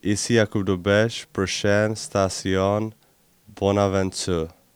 Les joueurs des Canadiens ont prêté leurs voix à la Société de transport de Montréal (STM) pour annoncer les arrêts sur la ligne orange à proximité du Centre Bell.